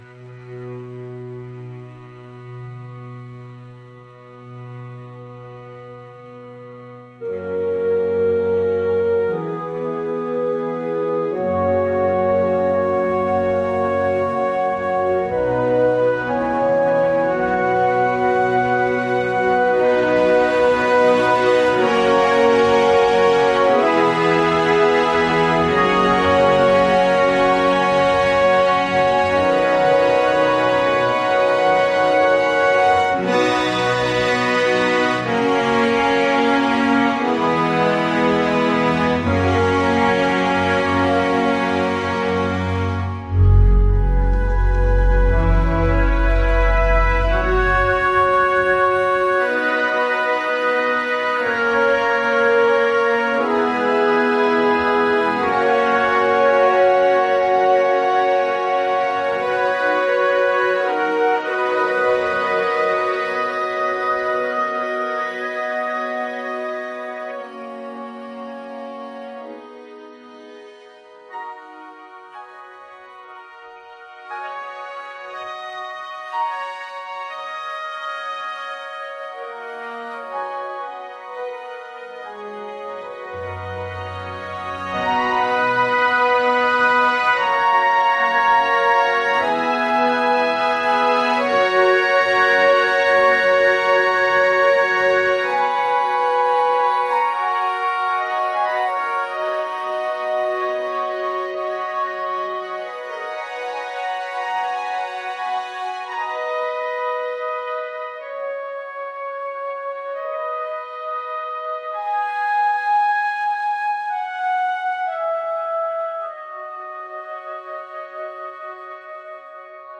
・メインセクションは、弦楽器の豊かなハーモニーが中心となり、壮大なホルンとフルートのメロディが曲の進行を支えます。
・アウトロは、再び壮大なハーモニーに戻り、湖畔の美しい景色と静けさを保ちながらフェードアウトします。